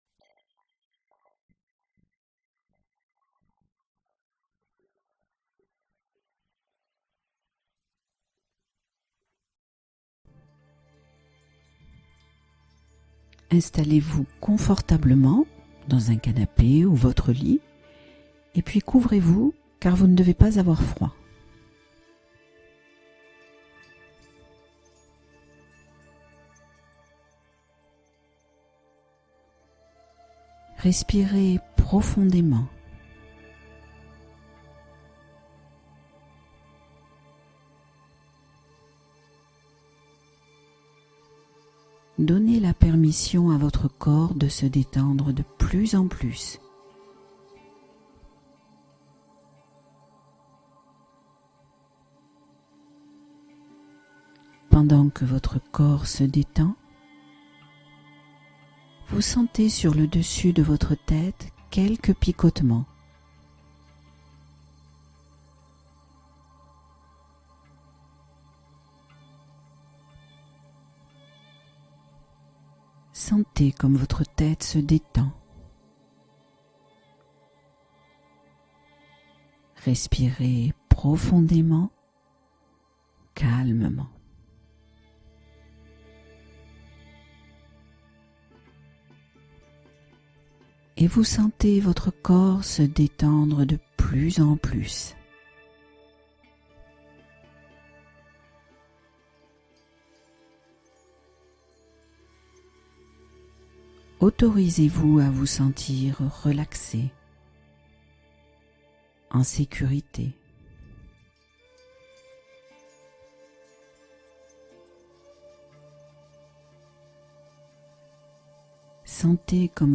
Le protocole du sommeil apaisant : guidé étape par étape vers des nuits réparatrices